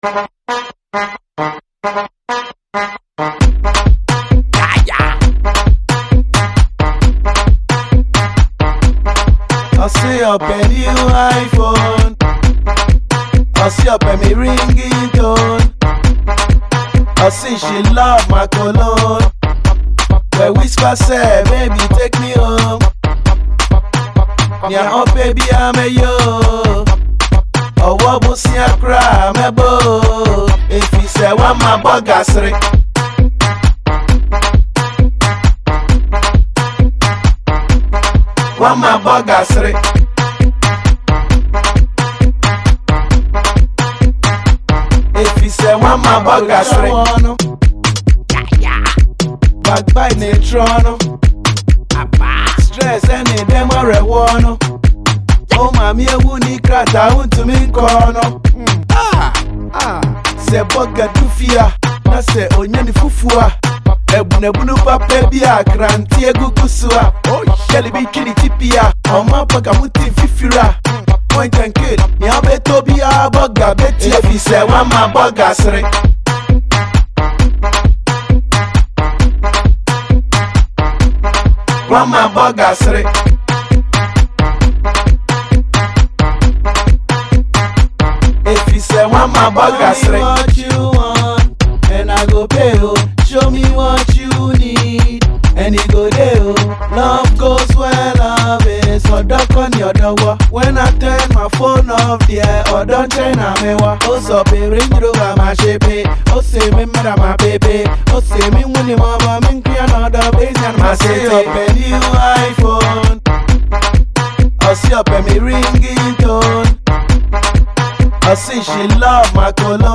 Ghana MusicMusic
Award-winning Ghanaian rapper